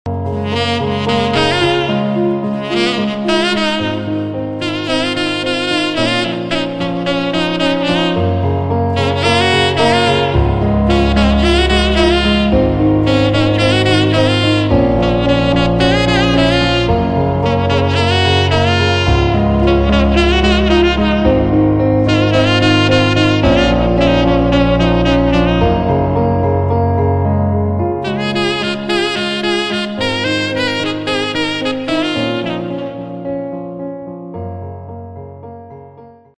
Saxophone Cover